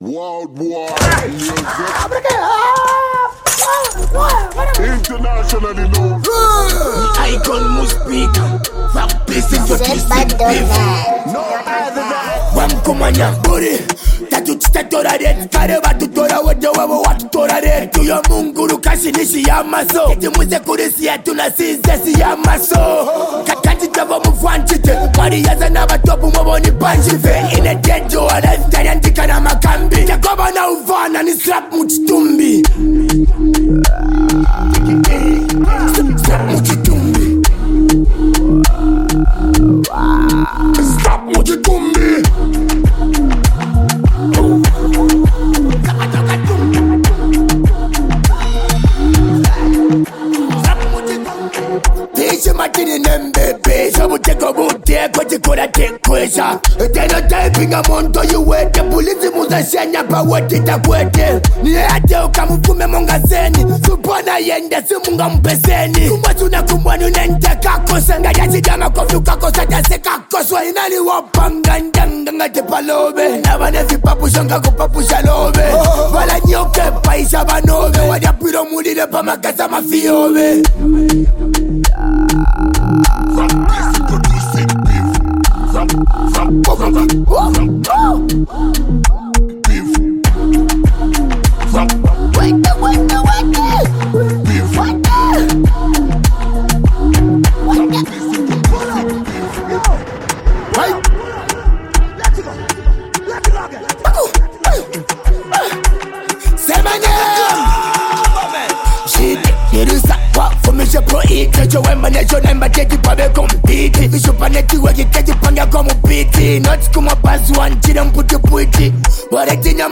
diss